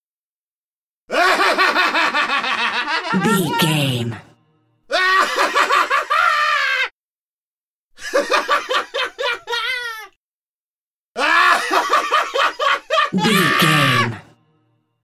Maniac laugh x4
Sound Effects
scary
disturbing
eerie
horror